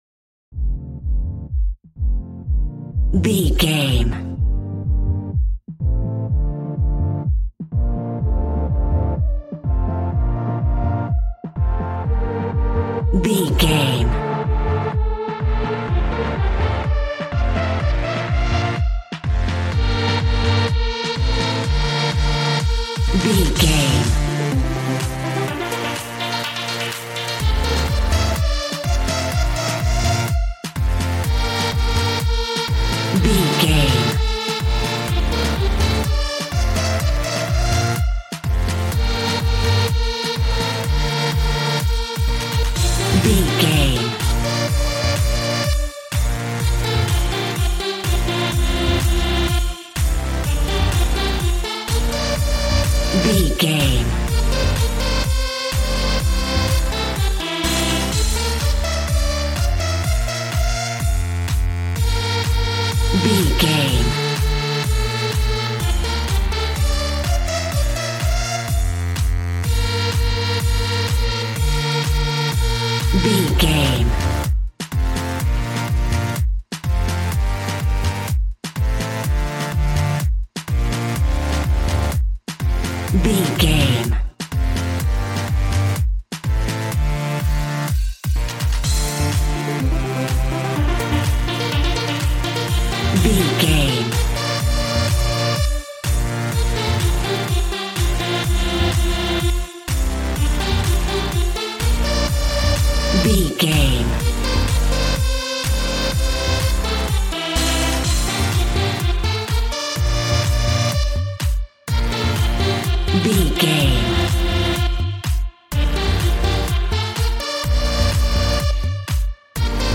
Aeolian/Minor
Fast
groovy
energetic
synthesiser
drums